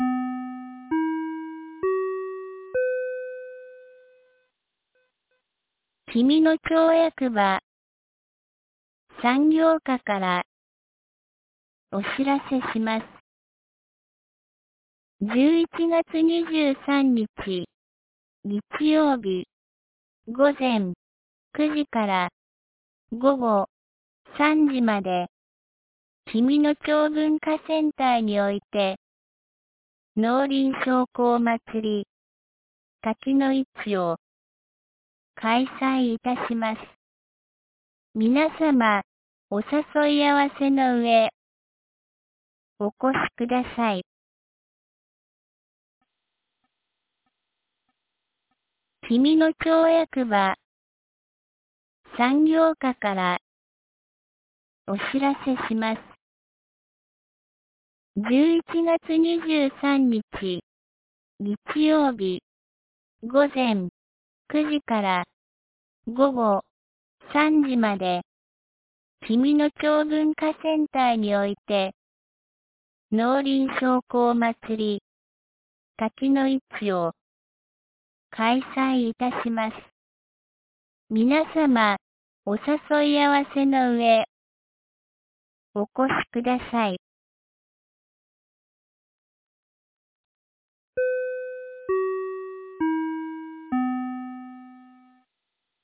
2025年11月22日 12時31分に、紀美野町より全地区へ放送がありました。